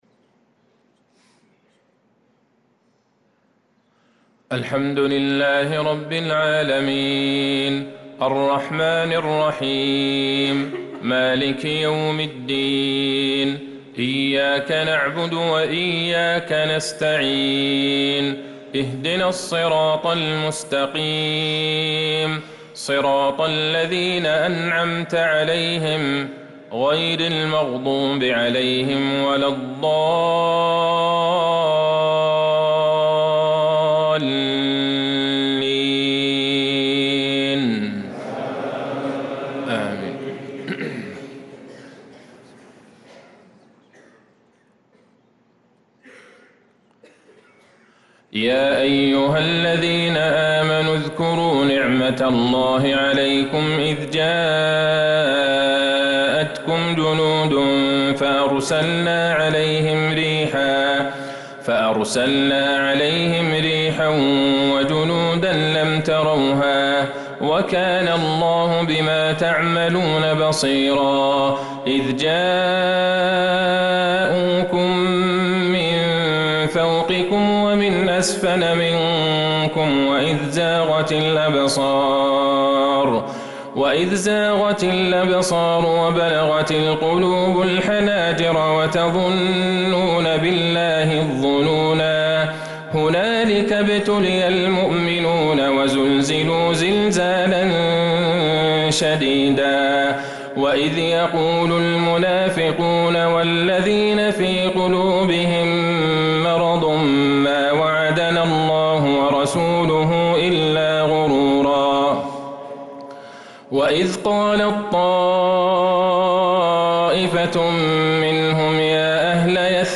صلاة العشاء للقارئ عبدالله البعيجان 5 شوال 1445 هـ